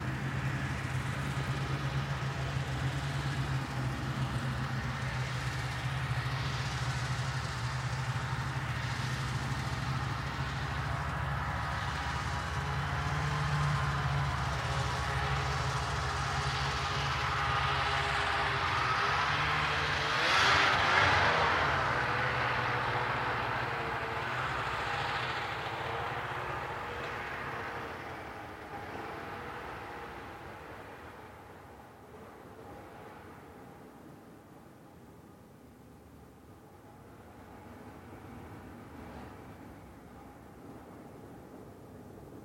摩托车离开
描述：摩托车启动和离开时有街道噪音
标签： 离开 摩托车 噪声 开始 街道 向上
声道立体声